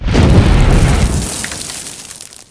coverblow.ogg